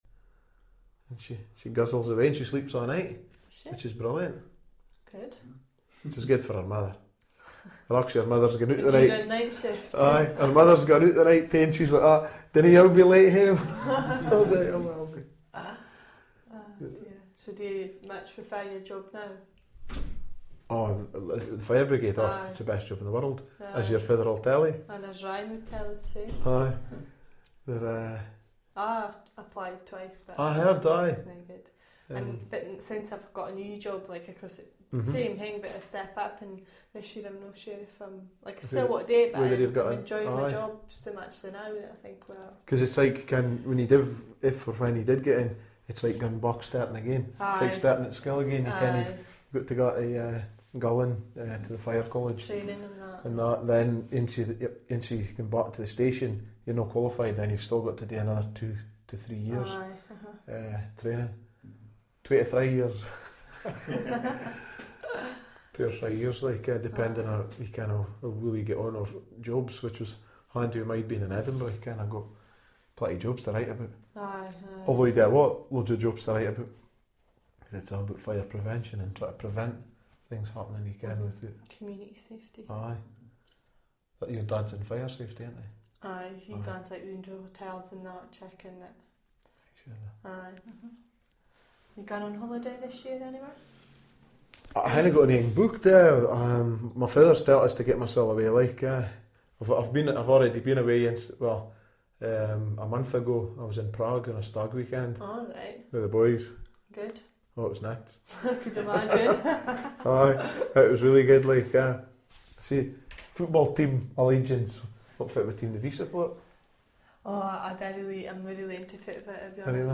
They are taken from the free conversation which was recorded as part of the fieldwork session. Informants were recorded in aged-matched pairs, as described above.
bullet Hawick younger speakers 2 [
hawick-younger-2.mp3